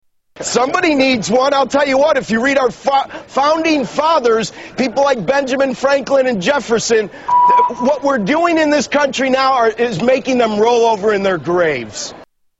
Tags: Rick Santelli Jon Stewart Jim Cramer Rants about the US economy Economic rants